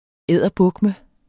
Udtale [ ˈeðˀʌˈbɔgmə ]